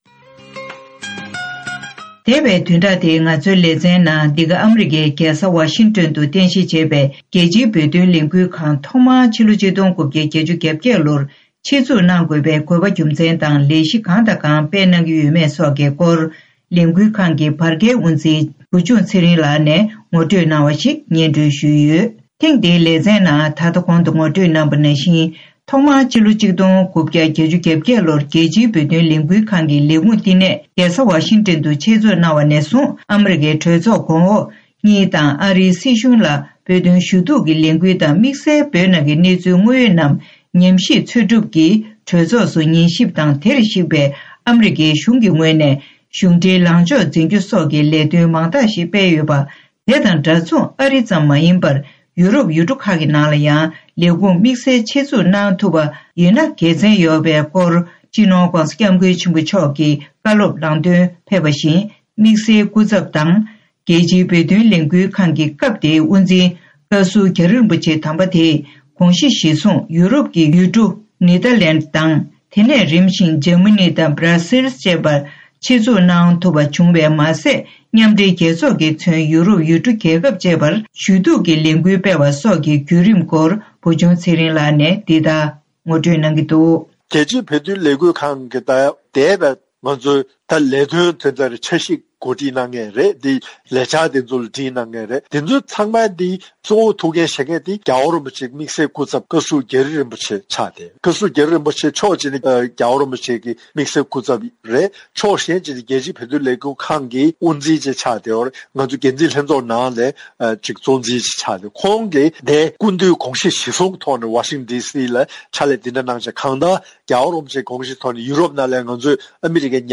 རྒྱལ་སྤྱིའི་བོད་དོན་ལས་འགུལ་ཁང་། ལེ་ཚན་གཉིས་པ། སྒྲ་ལྡན་གསར་འགྱུར།